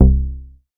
MoogAttackUpA.WAV